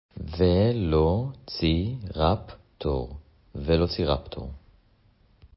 ולו-צי-רפ-טור